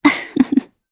Valance-Vox_Happy1_jp.wav